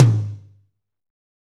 Index of /90_sSampleCDs/Northstar - Drumscapes Roland/DRM_Medium Rock/TOM_M_R Toms x